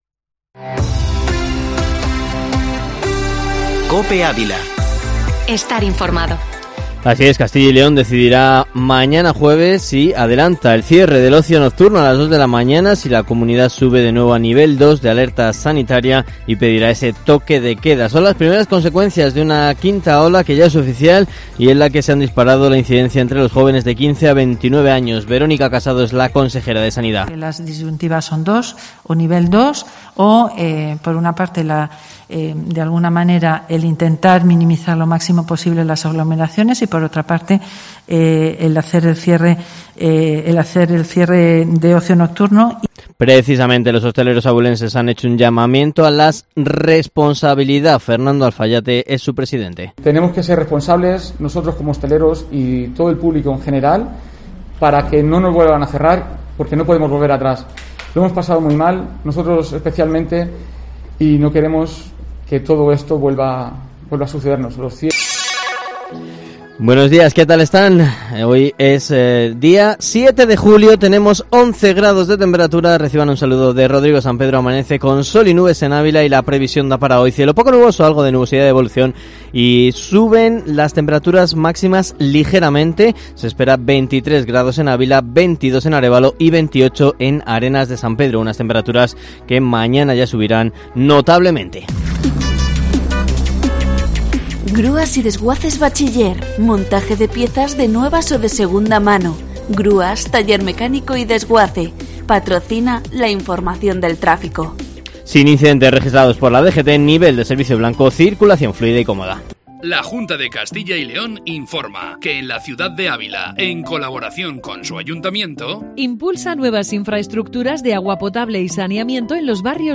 Informativo Matinal Herrera en COPE Ávila 7-julio